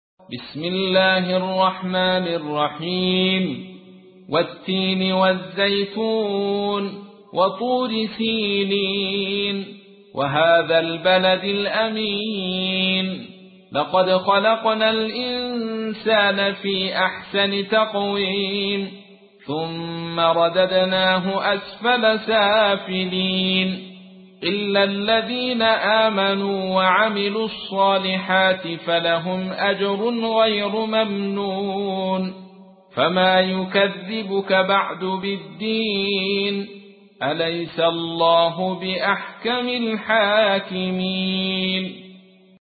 تحميل : 95. سورة التين / القارئ عبد الرشيد صوفي / القرآن الكريم / موقع يا حسين